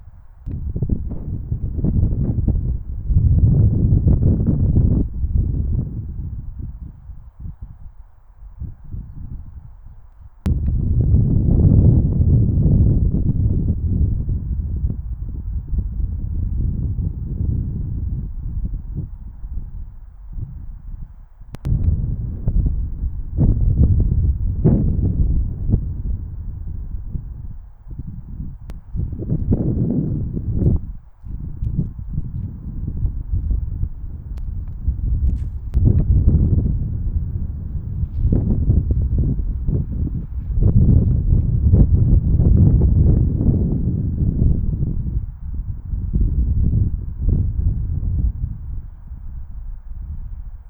tenkoku_windturbulence_low.wav